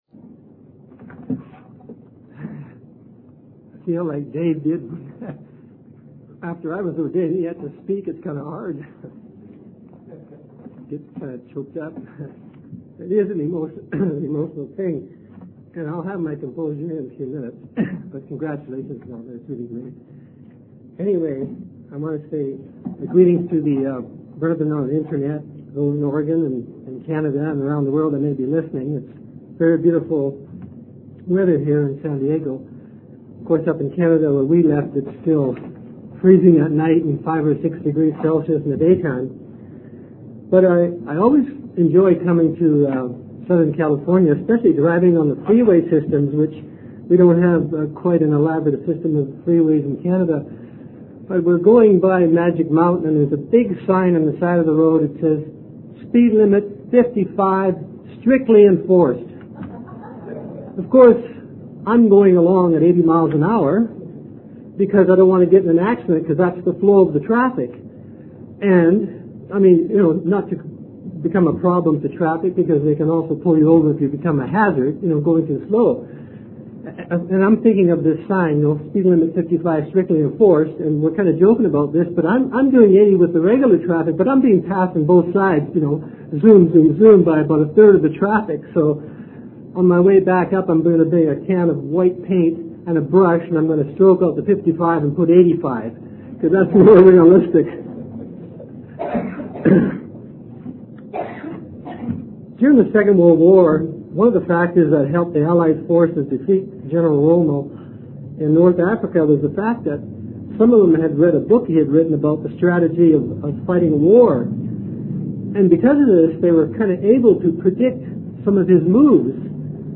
Sermons – Page 239 – Church of the Eternal God